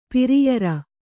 ♪ piriyara